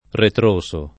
ritroso [ritr1So] agg. e s. m. — ant. retroso [